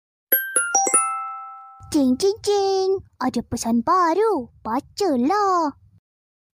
Genre: Nada notifikasi